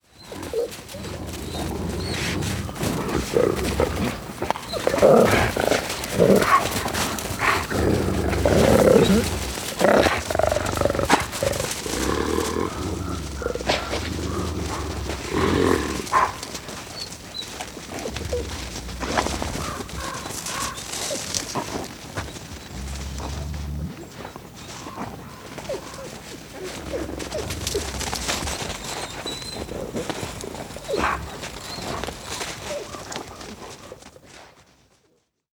Vielfrass-Geraeusche-Wildtiere-in-Europa.wav